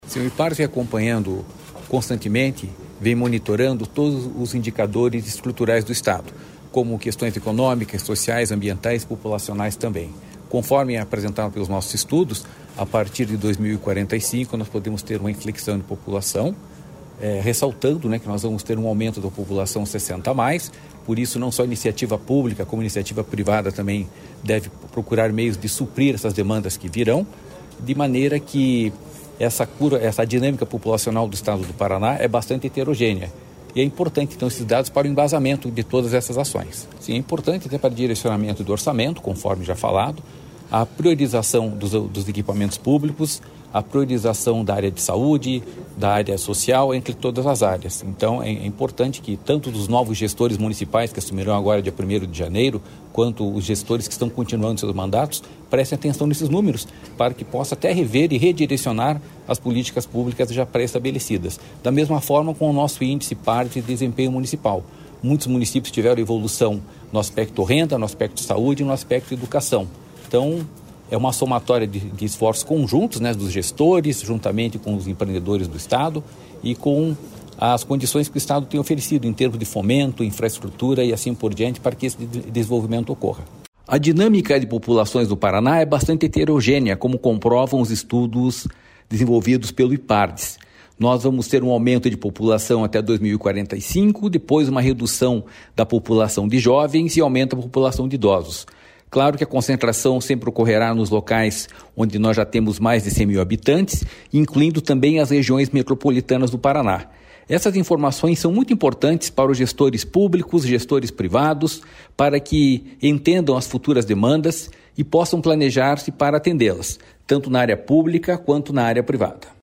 Sonora do diretor-presidente do Ipardes, Jorge Callado, sobre a projeção populacional do Instituto para 2050 no Paraná